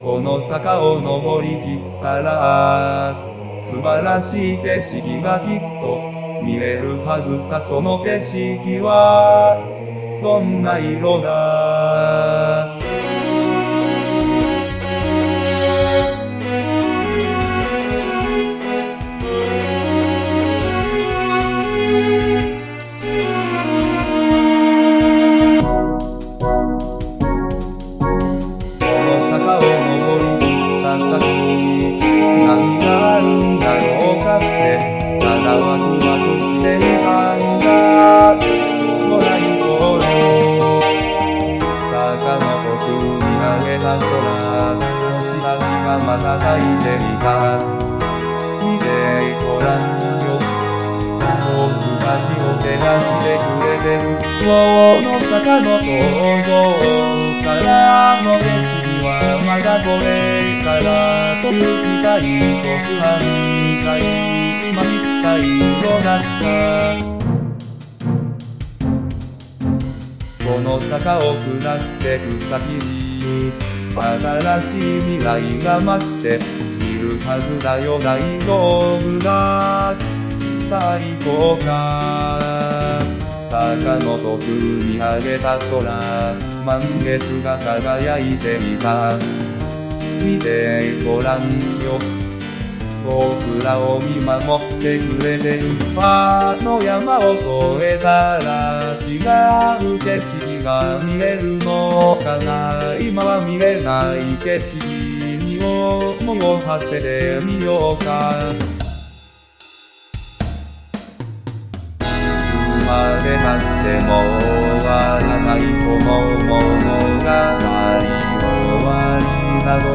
日本語歌詞からAI作曲し、伴奏つき合成音声で最長10分歌います。